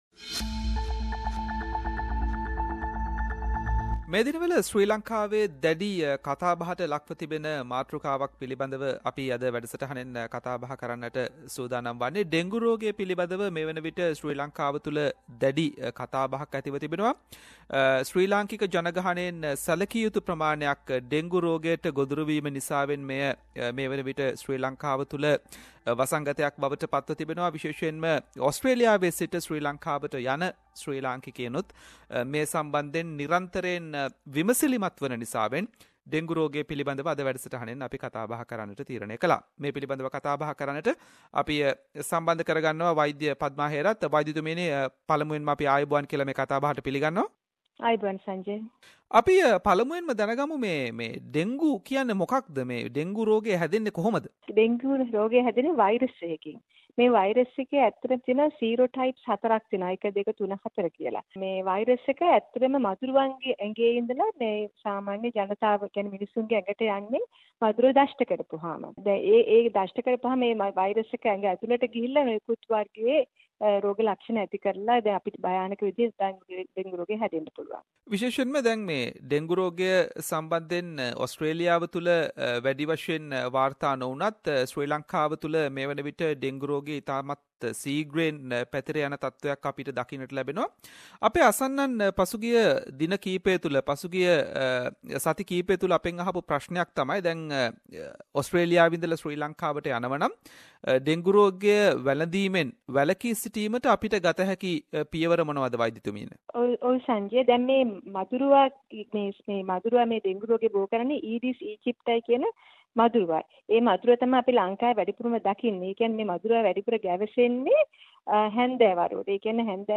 During the last seven months, nearly 90,000 Dengue fever cases were reported in Sri Lanka, its important to be aware of the Dengue if you are traveling to Sri Lanka these days. We have interviewed